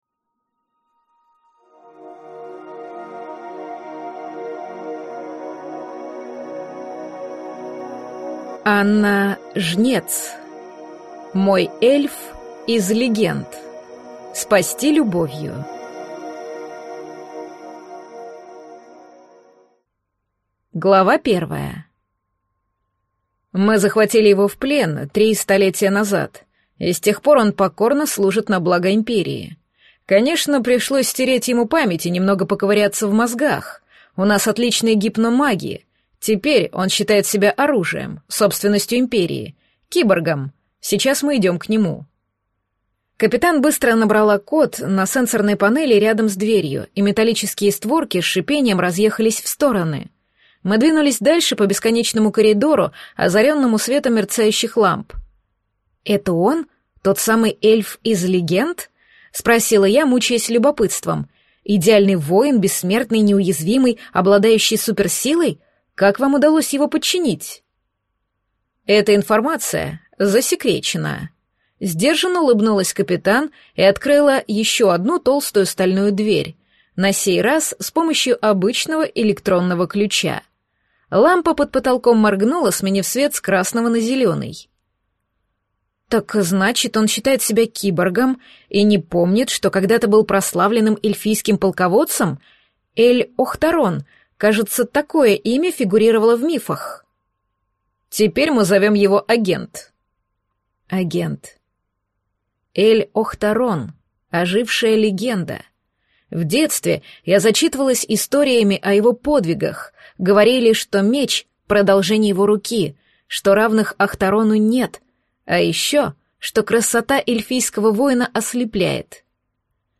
Аудиокнига Мой эльф из легенд. Спасти любовью | Библиотека аудиокниг
Прослушать и бесплатно скачать фрагмент аудиокниги